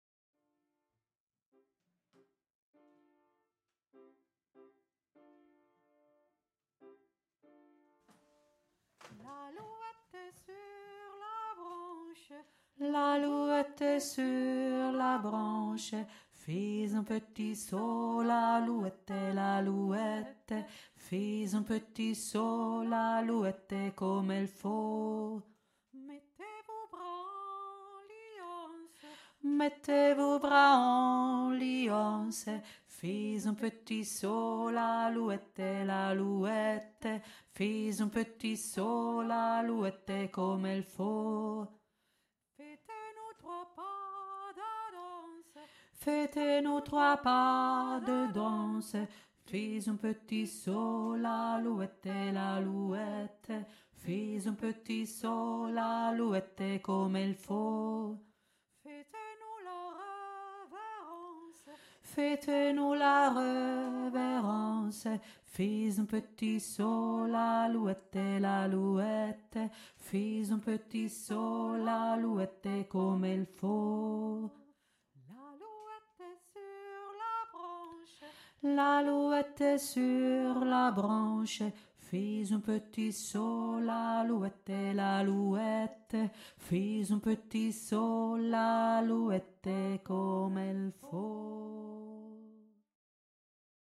Französisches Tanz-Lied
tiefe Stimme
l-aluette-tiefe-stimme.mp3